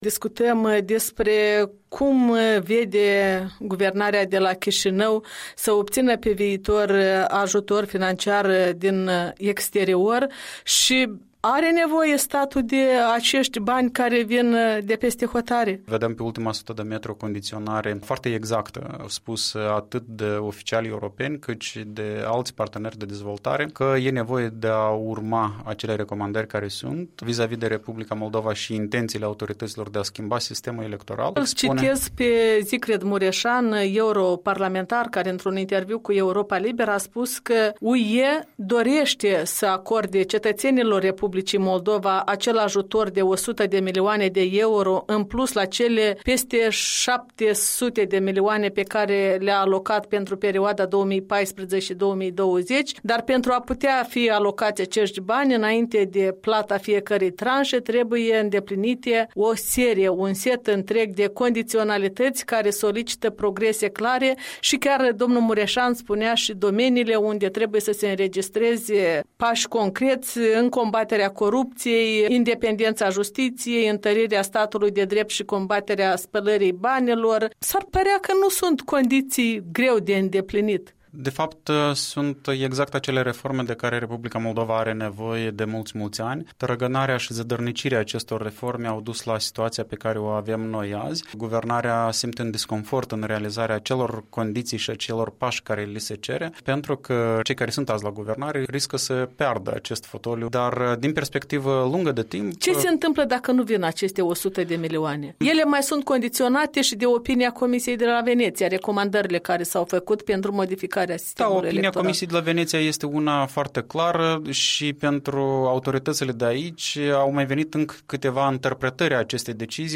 Un interviu cu fostul ministru de finanțe, expert la Tranparency International.